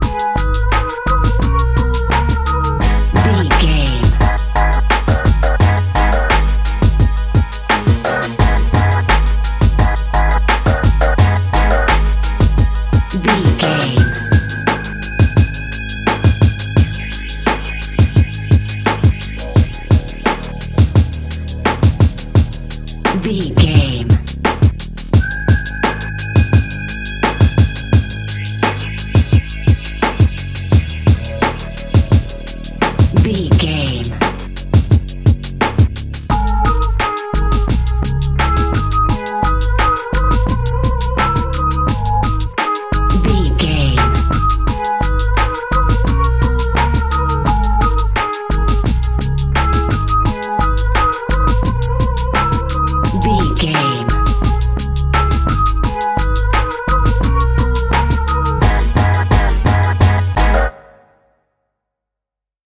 Aeolian/Minor
G#
SEAMLESS LOOPING?
drum machine
synthesiser
hip hop
Funk
acid jazz
energetic
bouncy
funky